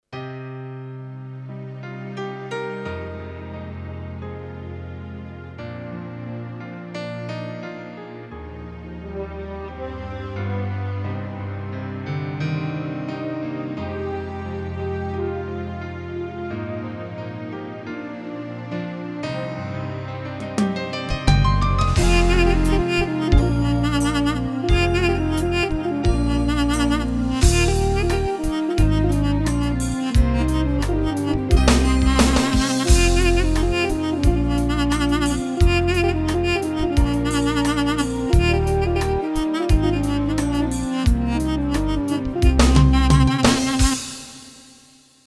Nefesli Çalgilar Samples
Asagida Dinlediginiz Sample Sesleri direk Orgla Calinip MP3 Olarak Kayit edilmistir